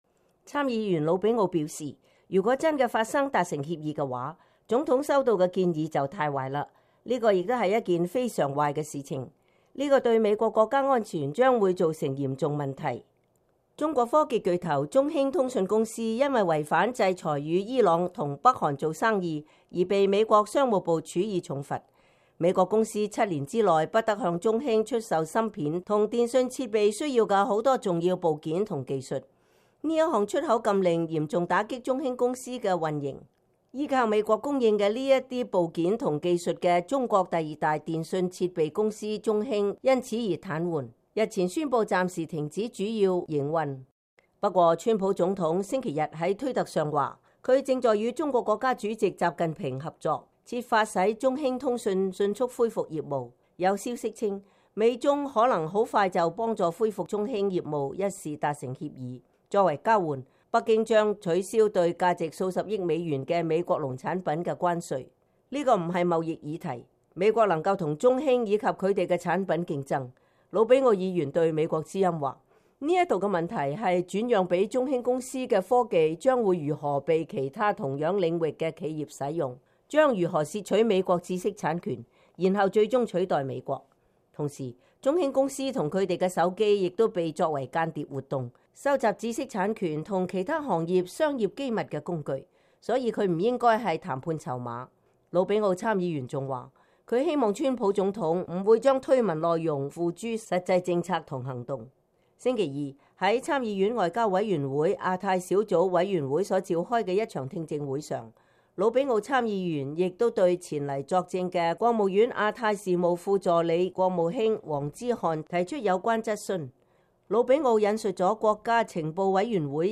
來自佛羅里達州共和黨籍參議員魯比奧（Sen. Marco Rubio, R-FL）星期二在參議院院會上發言，呼籲川普總統不要與中國達成任何協助中興通訊公司恢復運營的協議。